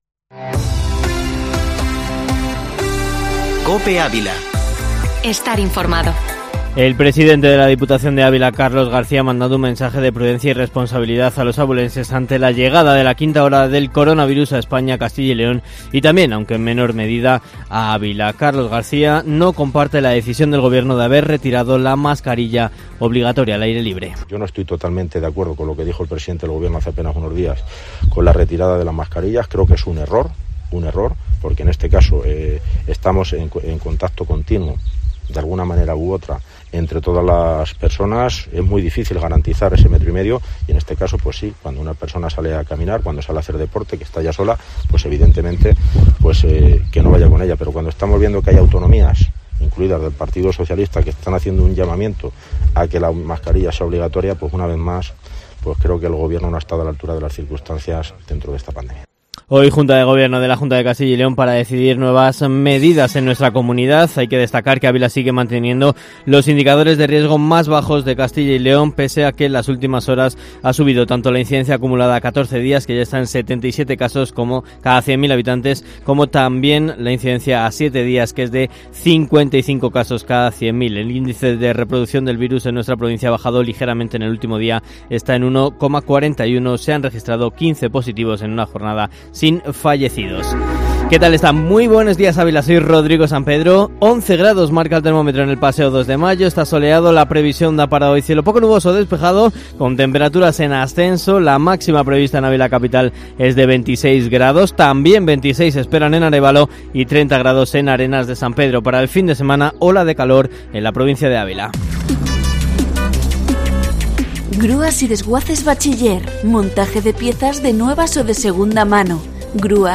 Informativo Matinal Herrera en COPE Ávila 8-julio